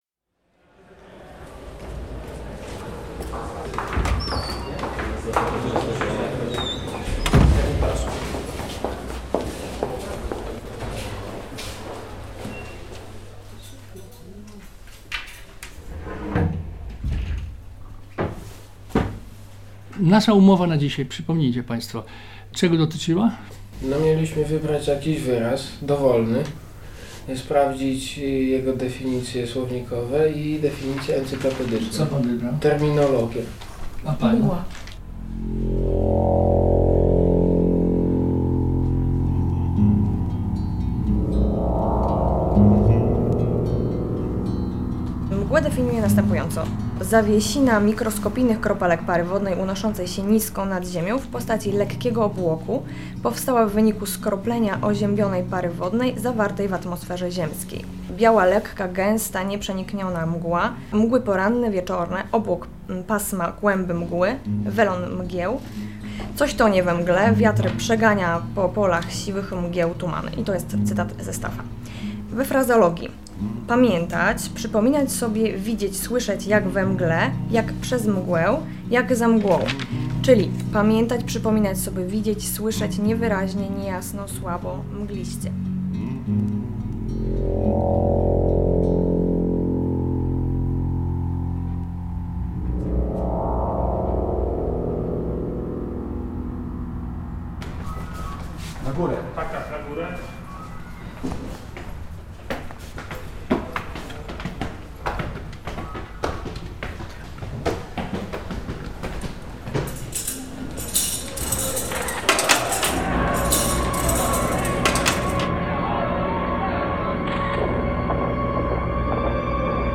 7 lutego odszedł od nas prof. Jerzy Bartmiński, wybitny językoznawca i opozycjonista. W reportażu nagranym w 2008 roku usłyszymy go podczas seminarium ze studentami i we wspomnieniach związanych z internowaniem.